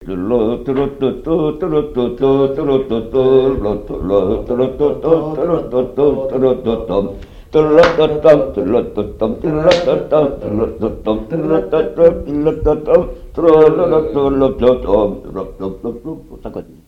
Chants brefs - A danser
danse : polka des bébés ou badoise
collecte en Vendée
Pièce musicale inédite